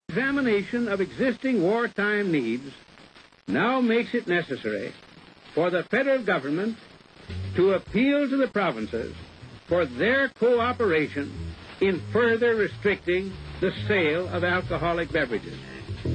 Dans un message radiophonique, King reserre les contrôles sur la production d'alcool, car il est irrité par cet "alcoolisme de guerre"(icône).